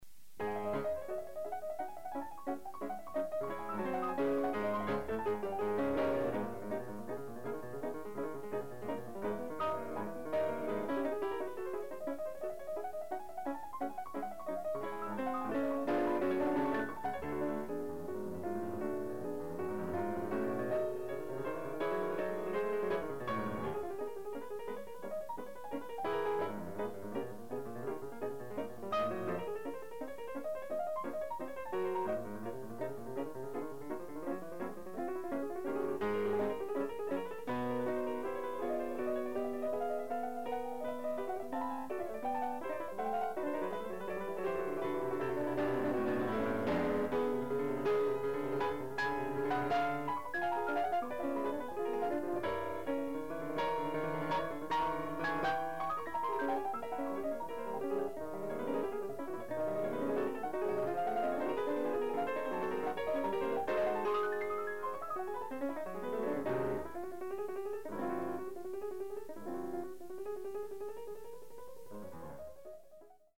Additional Date(s)Recorded September 17, 1973 in the Ed Landreth Hall, Texas Christian University, Fort Worth, Texas
Sonatas (Piano)
Short audio samples from performance